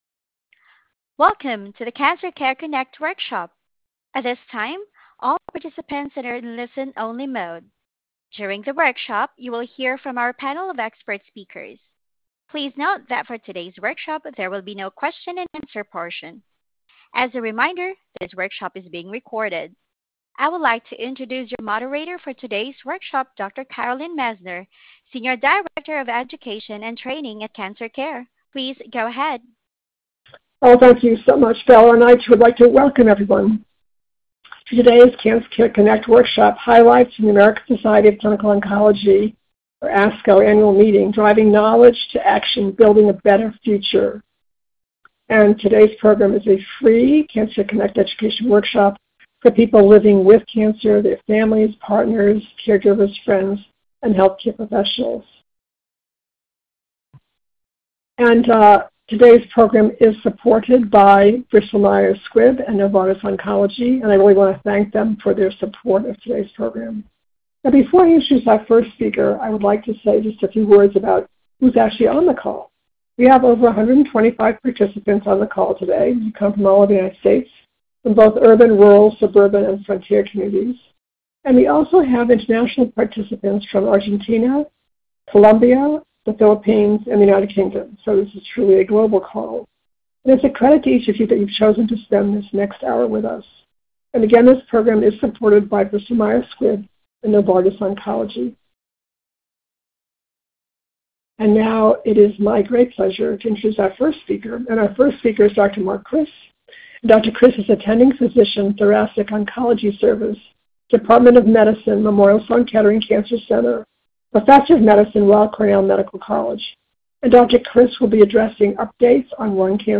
This workshop was originally recorded on August 12, 2025.